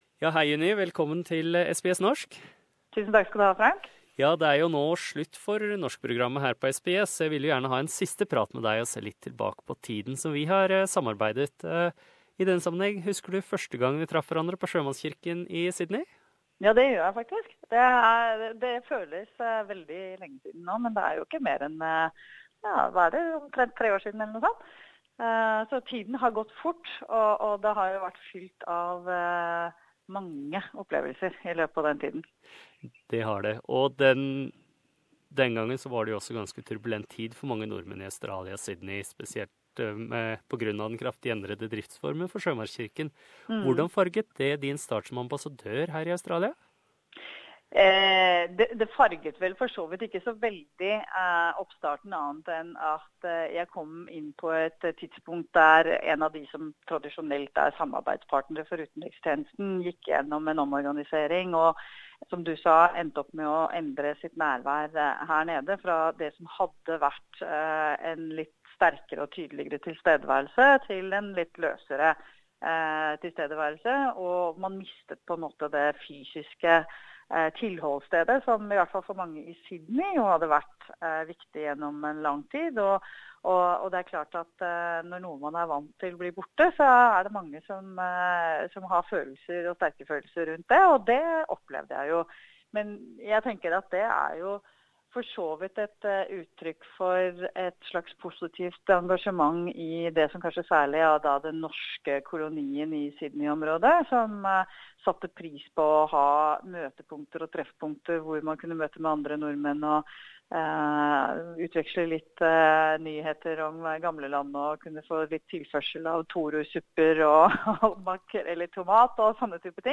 The last of many interviews with the Ambassador